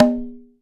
084 - CongaLo1.wav